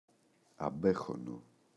αμπέχονο, το [aꞋbexono]